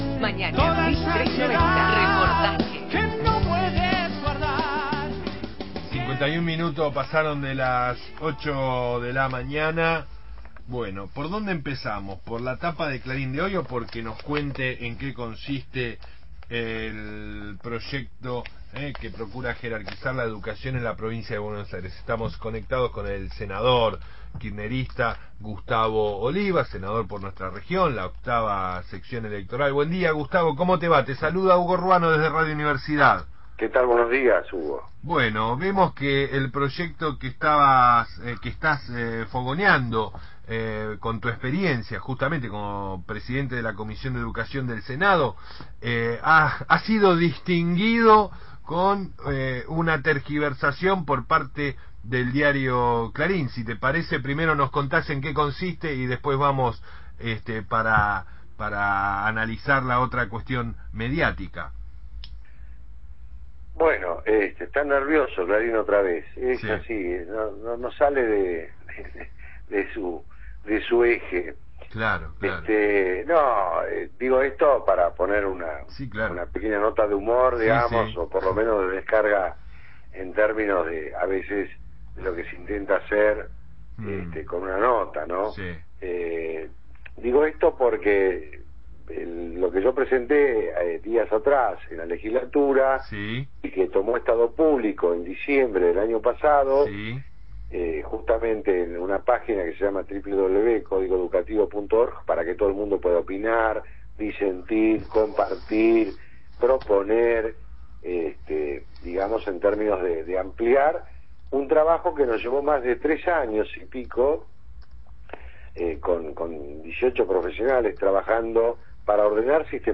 El senador provincial por el Frente Para la Victoria, Gustavo Oliva, dialogó